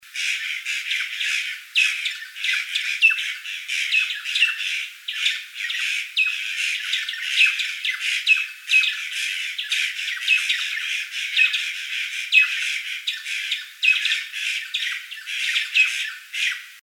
Urraca Morada (Cyanocorax cyanomelas)
En la zona de acampe de la reserva,habia varias junto con la Urraca Común.
Nombre en inglés: Purplish Jay
Localidad o área protegida: Parque Nacional Río Pilcomayo
Condición: Silvestre
Certeza: Fotografiada, Vocalización Grabada
Urraca-Morada-R.N-R.Pilcomayo.MP3